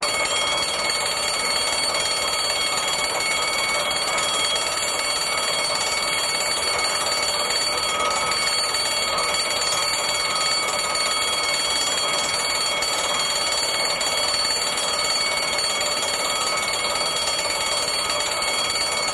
Alarms, Low Pitched, Burglar Alarm.